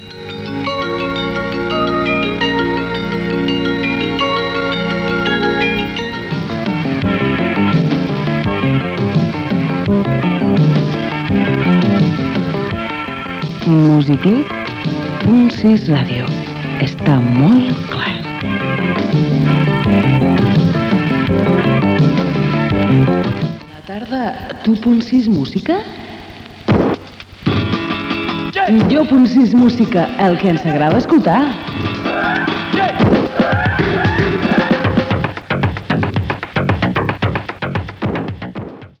Indicatiu de l'emissora i de la radiofórmula Punt Sis Música